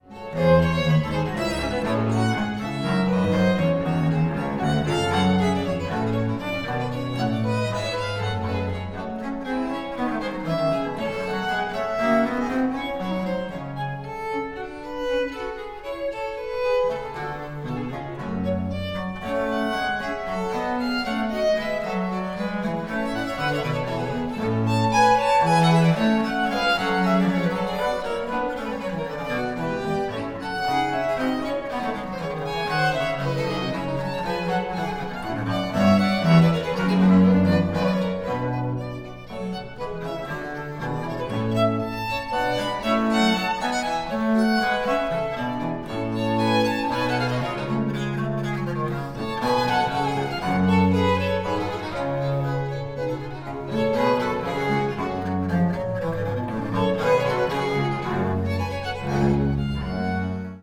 on authentic instruments:
violin
bass viol
theorbo
chamber organ and harpsichord
Recorded 27-29 October 2013 at Menuhin Hall, Surrey, England